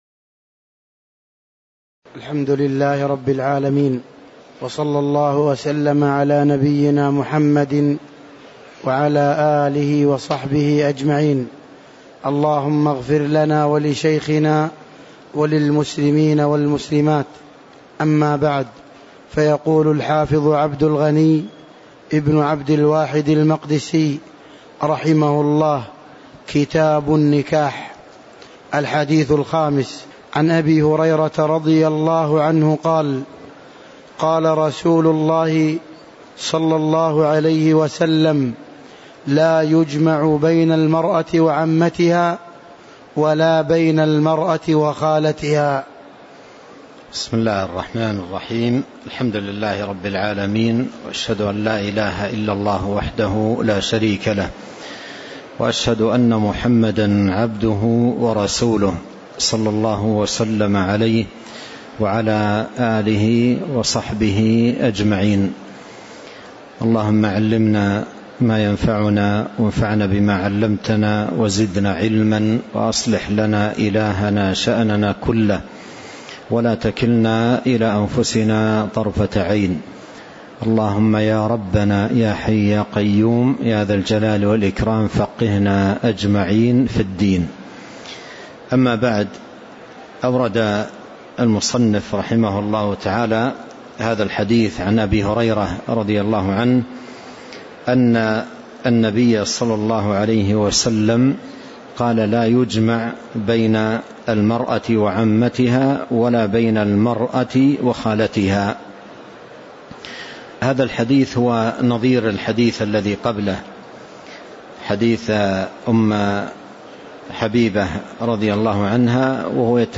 تاريخ النشر ١٤ رجب ١٤٤٤ هـ المكان: المسجد النبوي الشيخ